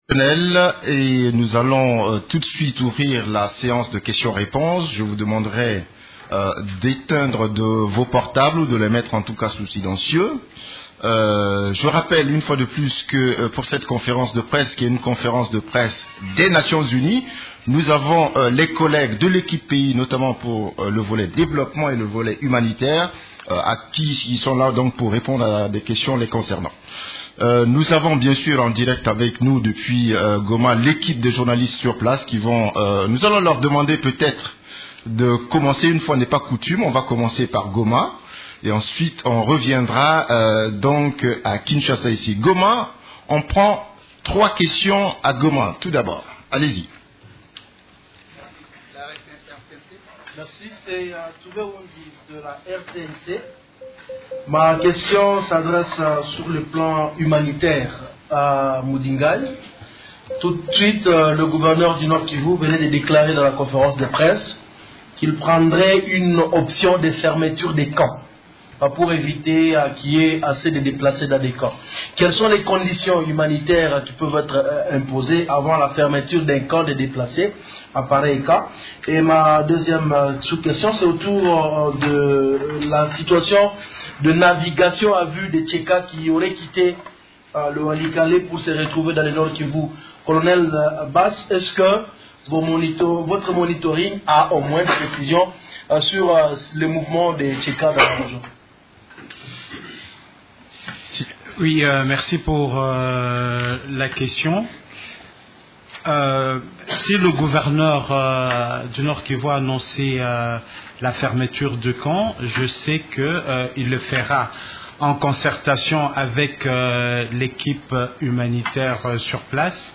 Conférence des Nations unies du mercredi 16 avril 2014
La conférence hebdomadaire des Nations unies du mercredi 16 avril à Kinshasa a abordé les sujets suivants: